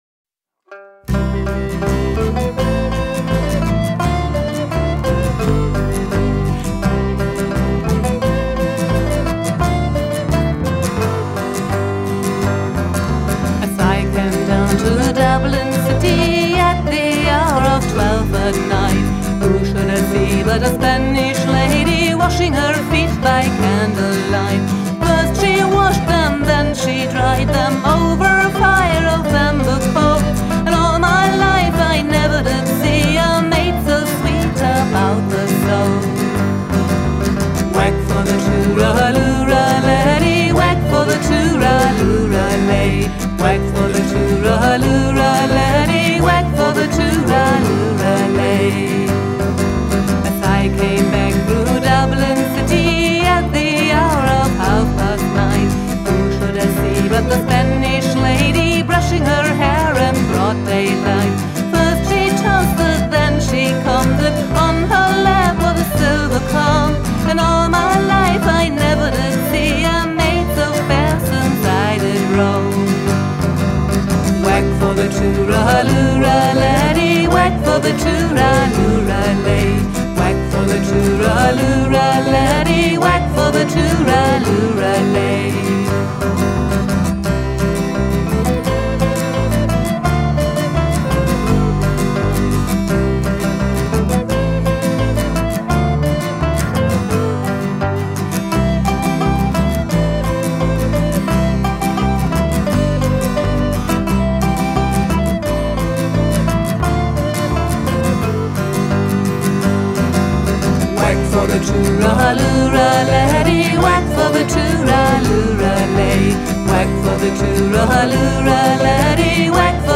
aufgenommen im Oktober 2023 im Tonstudio